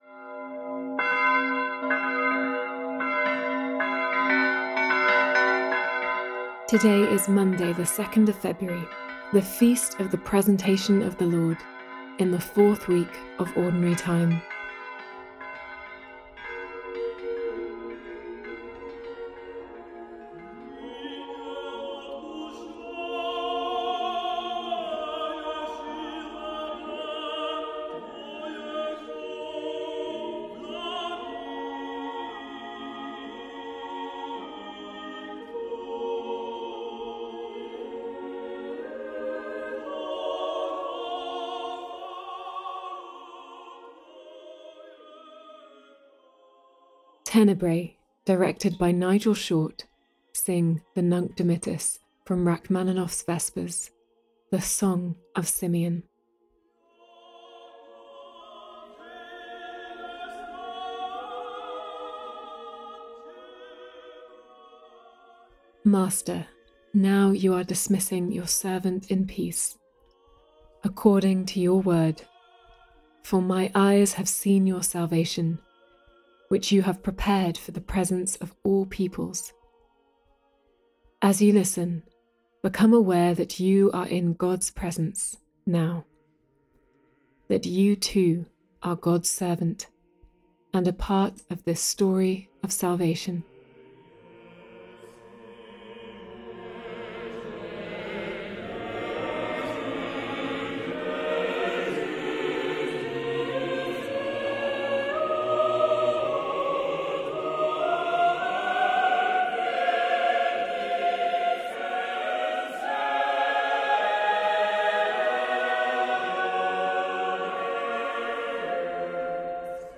Today’s reading is from the Gospel of Luke.